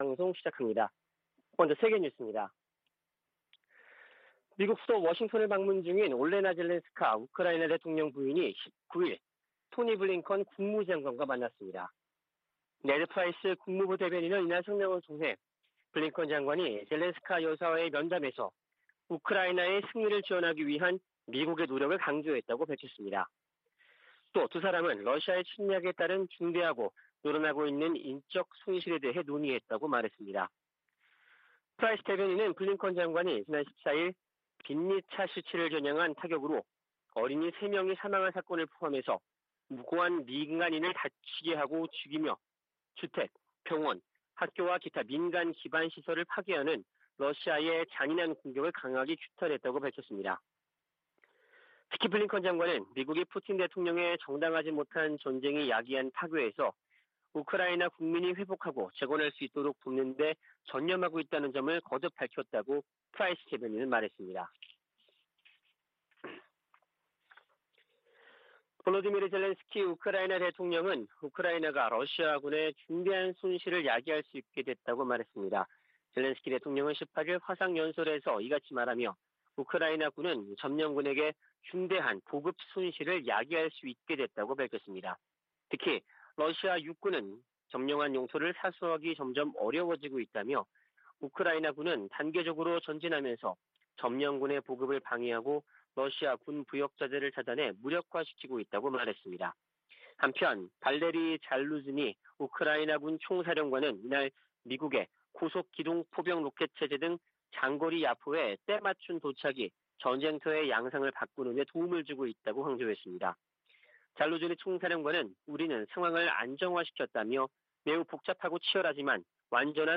VOA 한국어 '출발 뉴스 쇼', 2022년 7월 20일 방송입니다. 주한미군은 미한 공동 안보 이익을 방어하기 위해 필요하다는 입장을 미 국방부가 확인했습니다. 한국을 방문한 미 재무장관은 탄력성 있는 공급망 구축을 위한 협력을 강조하며 중국의 시장 지배적 지위를 막아야 한다고 말했습니다. 미 국무부가 북한의 인권 상황은 대량살상무기 만큼이나 우려스러운 부분이라고 지적했습니다.